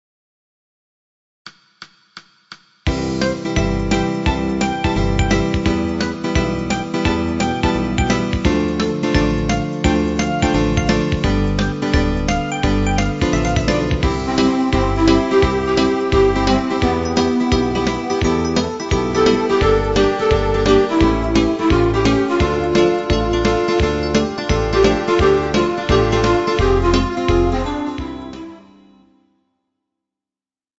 • Backing Track: Midi File